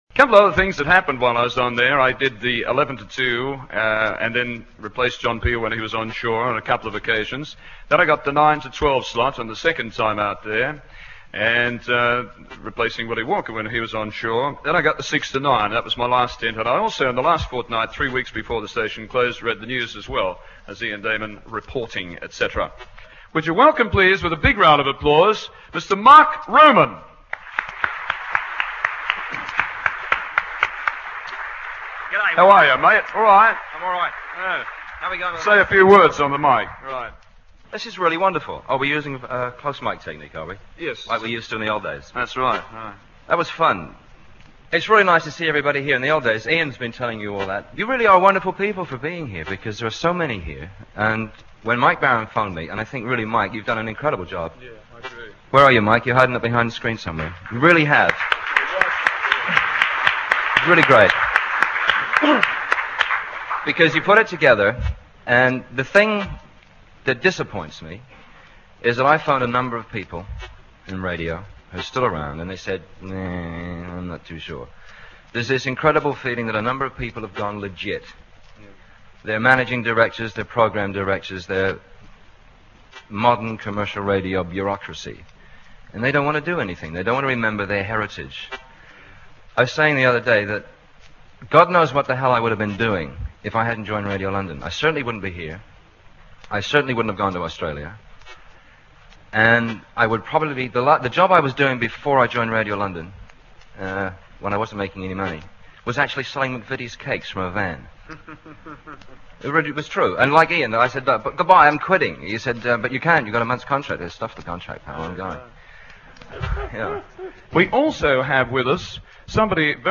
Unfortunately the proximity of Heathrow Airport caused problems for the PA system and there was audible interference from radar scanners, both on the day and on the record.
take to the stage to talk about their time at sea.